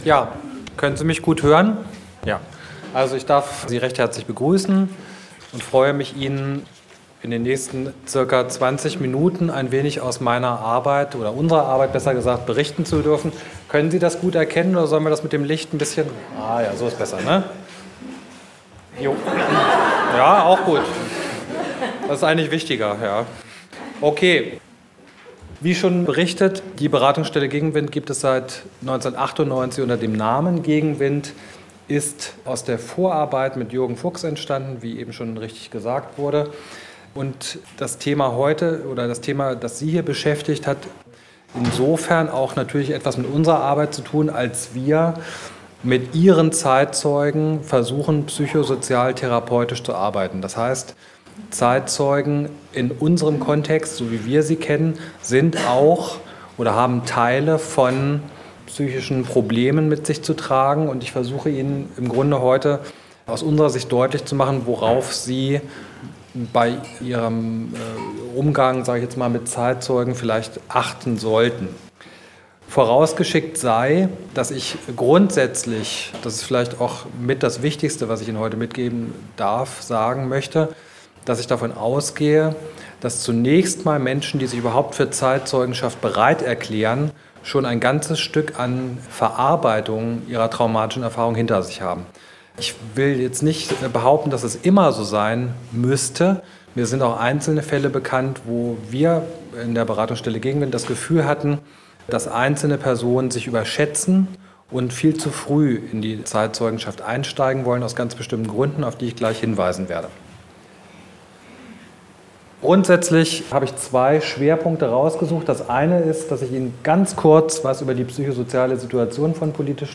Vortrag und Gespräch | Sensible Begegnungen – Zeitzeugenarbeit mit Opfern der SED-Diktatur